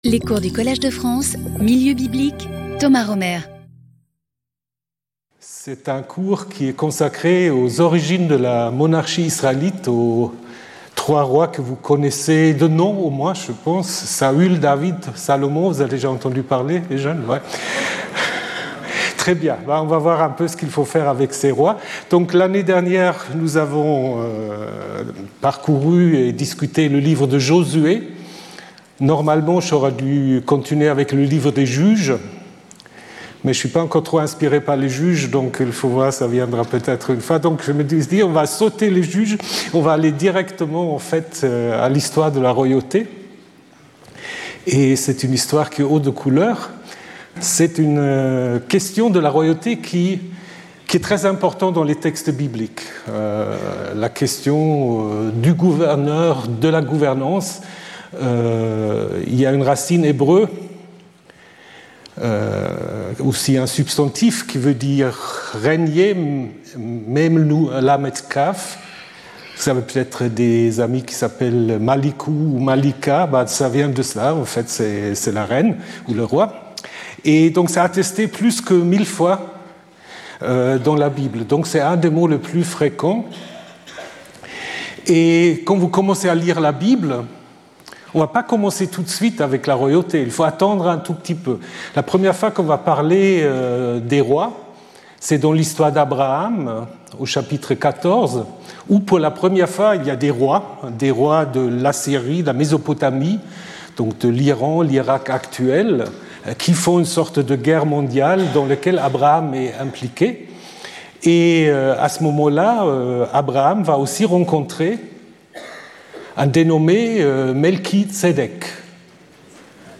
We will briefly trace the formation of these texts. Speaker(s) Thomas Römer Professor and Administrator of the Collège de France Events Previous Lecture 12 Feb 2026 14:00 to 15:00 Thomas Römer Saul, David, Solomon: mythical or historical figures?